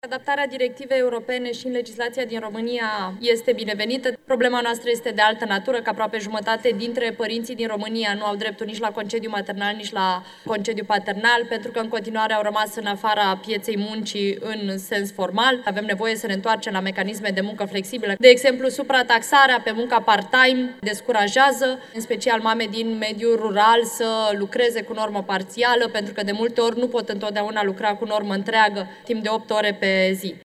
La dezbaterea proiectului deputata Oana Țoiu a atras atenția că jumătate dintre părinții din România sunt în afara muncii, iar cei care ar putea lucra cu jumătate de normă sunt descurajați de taxele uriașe pentru astfel de activități.